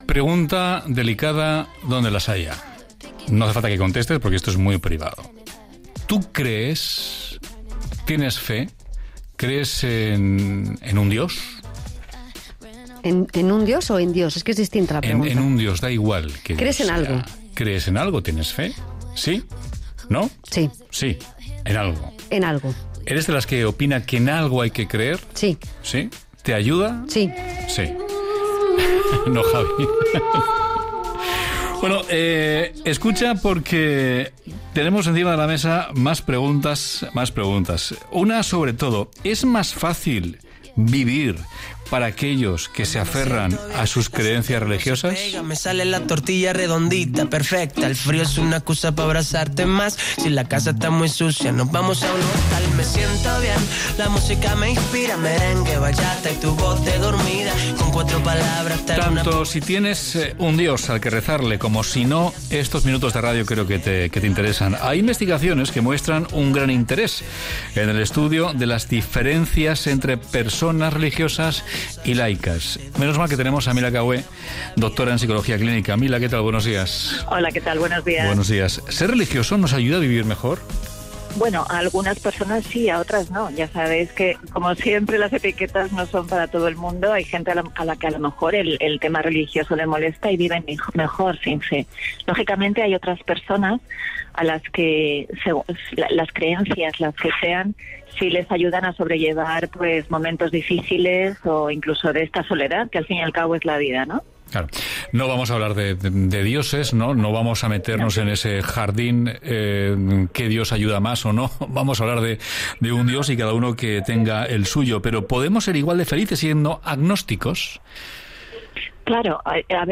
Podcast: «¿Es más fácil vivir si se tienen creencias religiosas?», para Aragón Radio, 12 Junio 2018